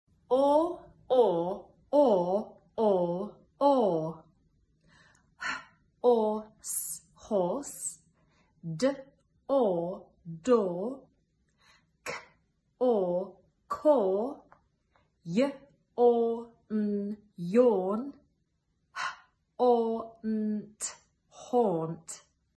or, oor, ore, aw, au all make the same sound. horse (or) door (oor) more (ore) yawn (aw) Paul (au) When children are developing their reading, it can help to identify these complex sounds first in words.